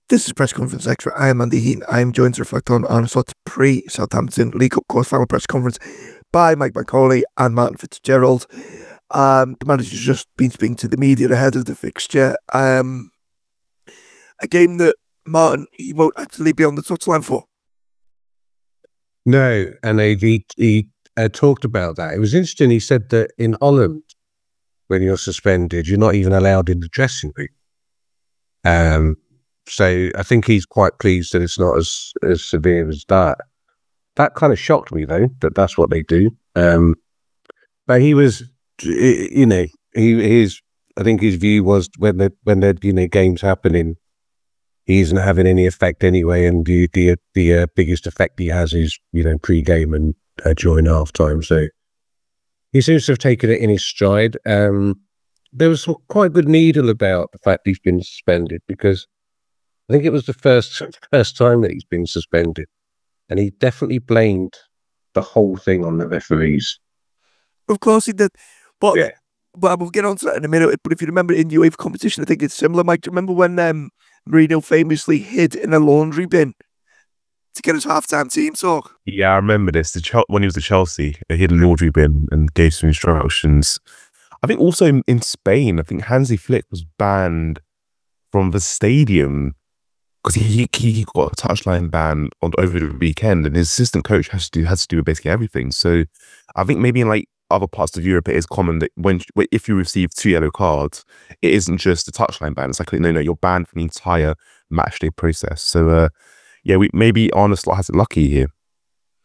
Below is a clip from the show – subscribe for more on the Liverpool v Fulham press conference…